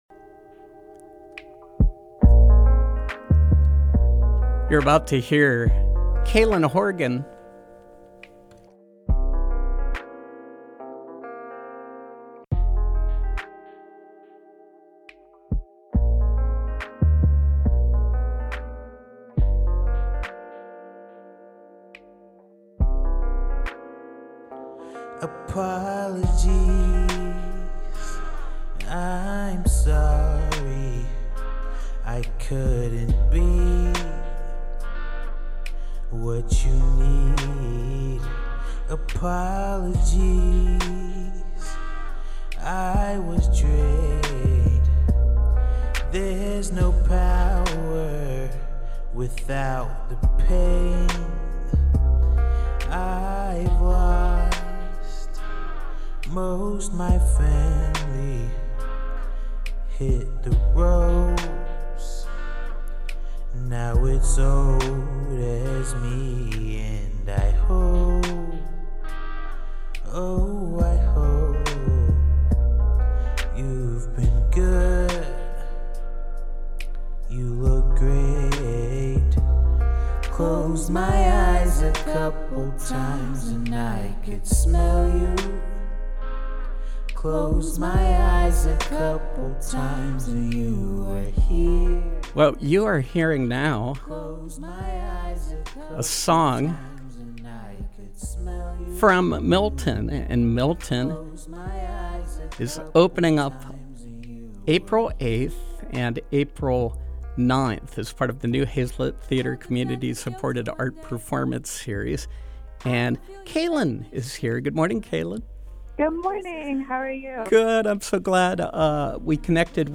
Interview: CSA Performance Series, Milton